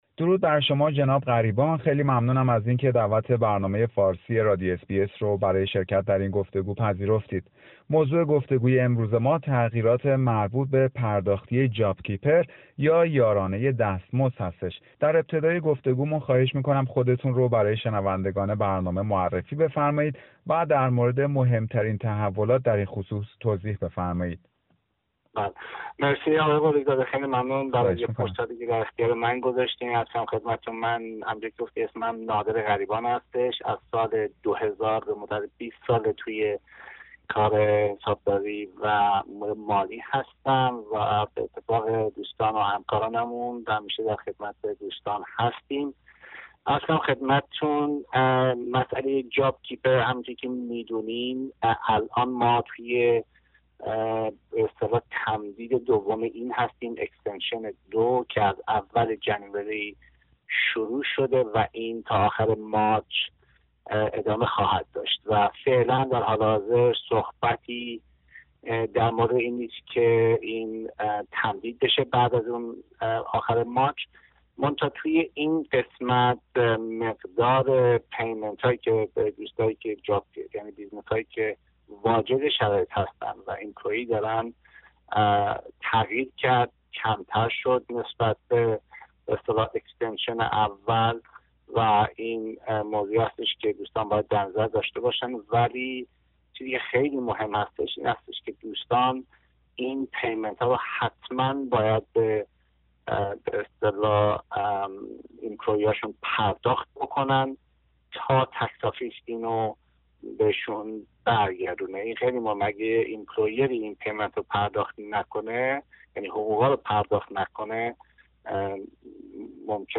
گفتگویی در مورد پرداختی های JobKeeper‌ و JobMaker